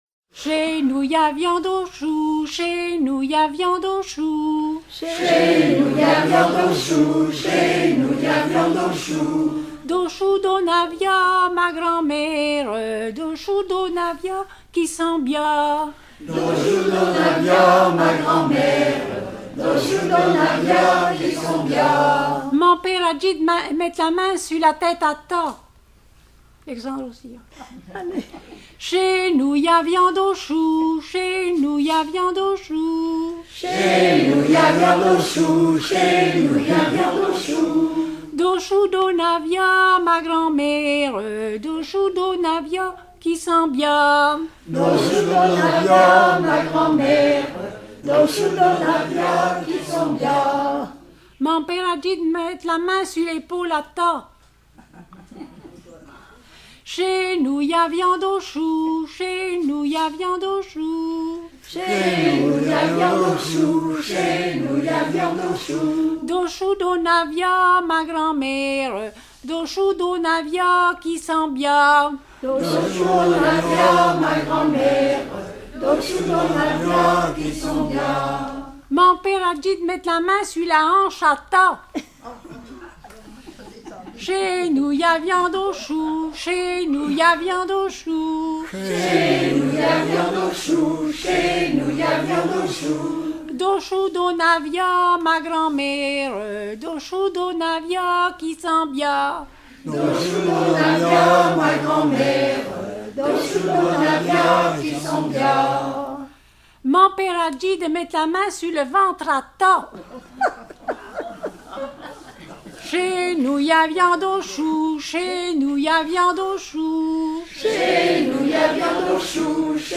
Bocage vendéen
danse : ronde : la limouzine
Genre énumérative
Pièce musicale éditée